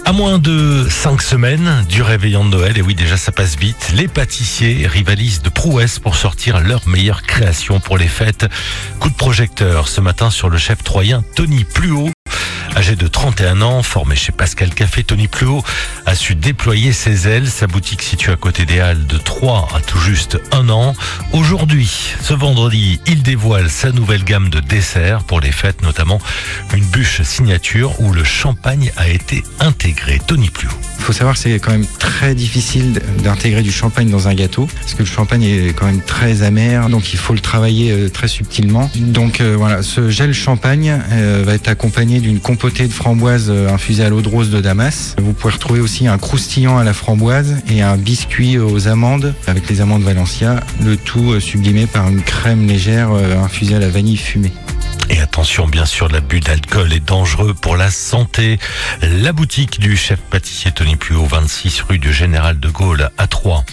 Reportage Chérie FM Noël 2025